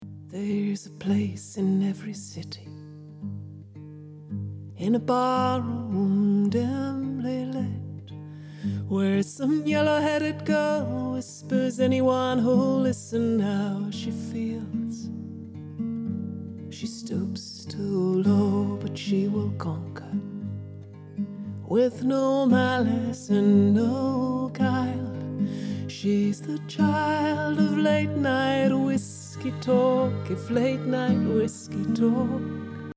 ensuite j'ai activé sur ce même compresseur opto le side-chain avec la fréquence vraiment ciblé sur les sifflantes (autour de 7kHz) mais sans rien toucher d'autres aux réglages
==>>l'audio compress avec side-chain 7kHz; le résultat est assez catastrophique comme on peut l'entendre (j'ai expliqué plus haut pourquoi il faut utiliser le side-chain avec prudence....et pourquoi il faut éviter des réglages extrêmes pour la fréquence)
opto_comp_side.mp3